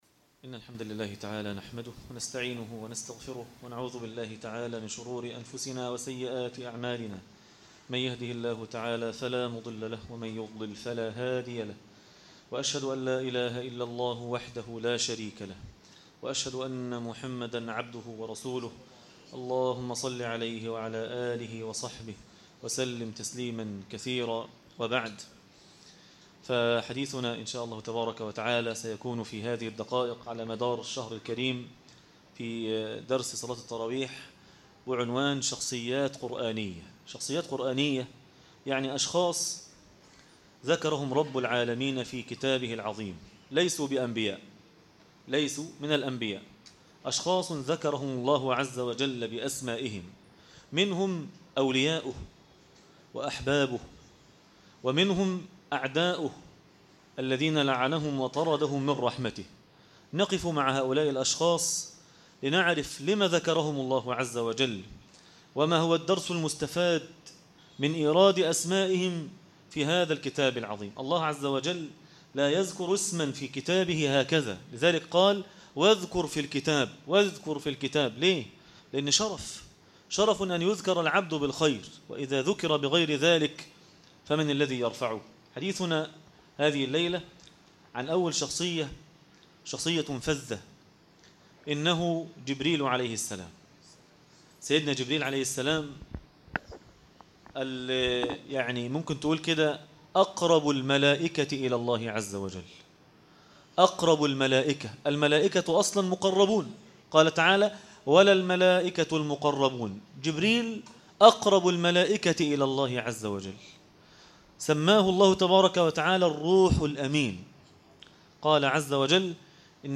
جبريل عليه السلام - درس التراويح